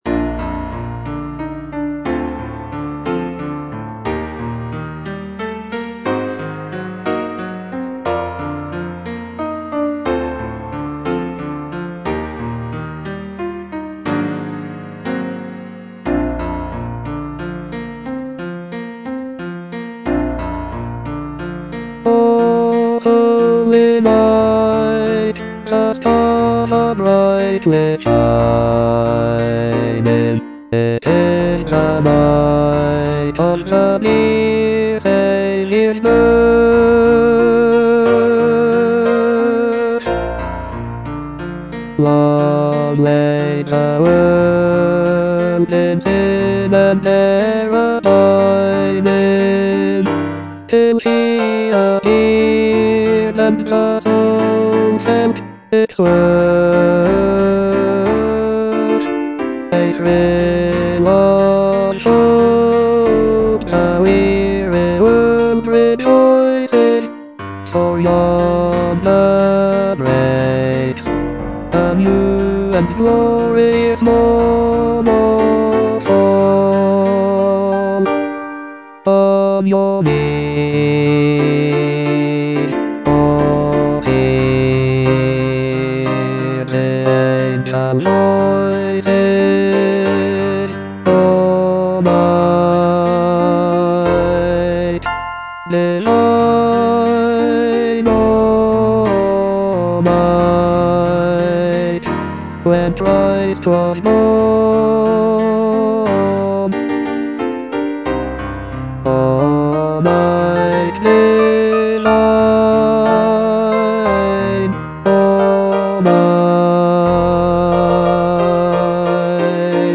Bass (Voice with metronome)